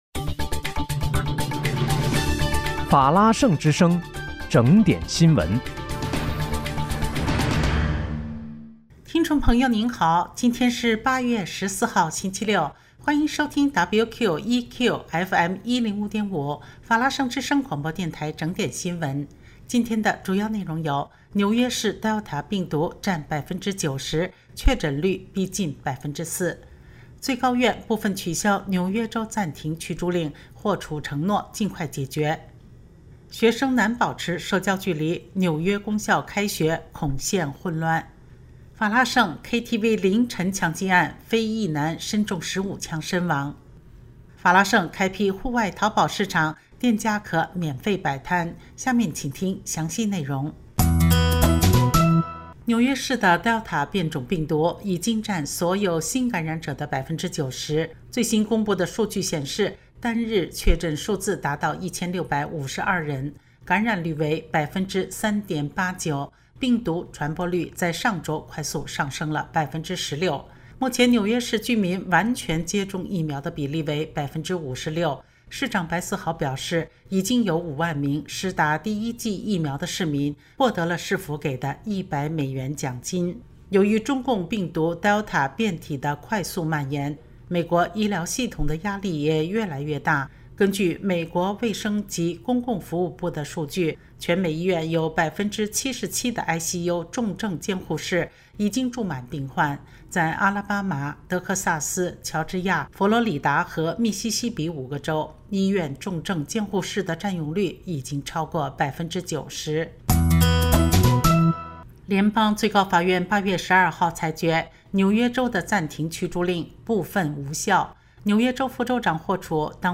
8月14日（星期六）紐約整點新聞